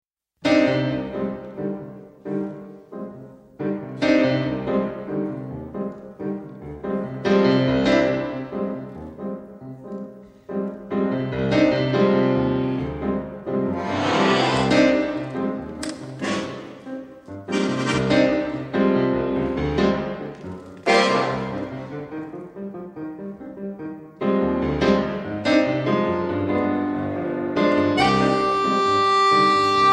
revisited in jazz ways
accordion
piano